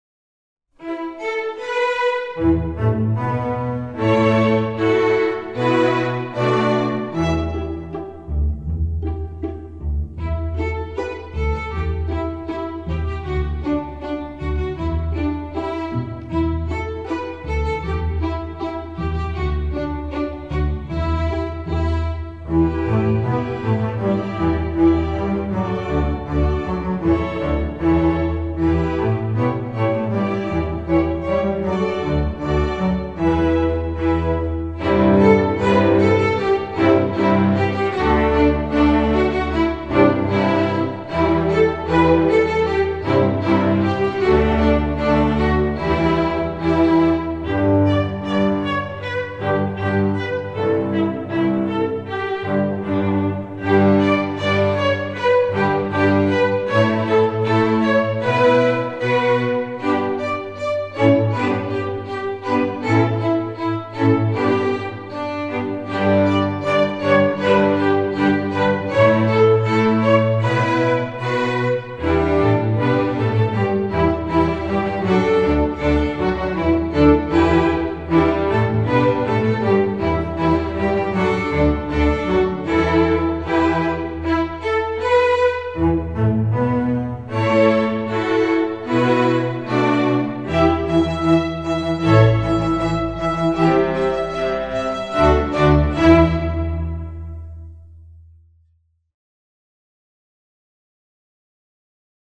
classical, children, instructional